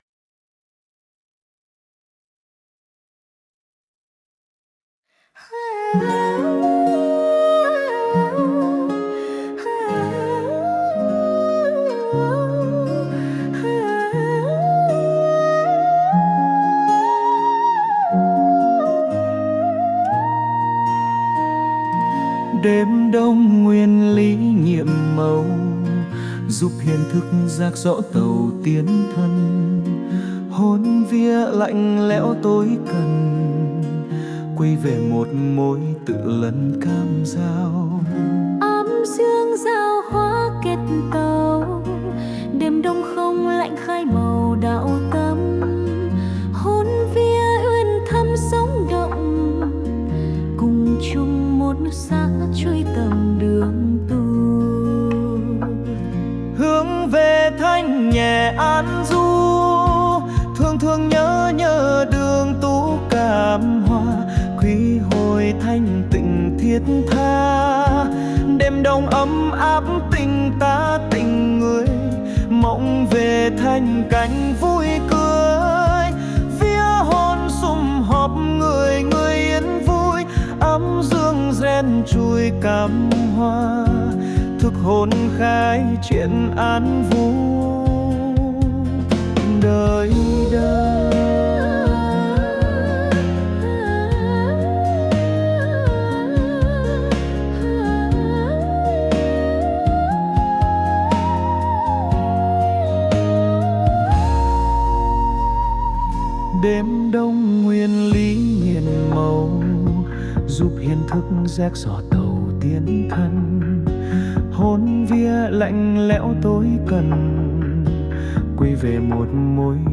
NHẠC THƠ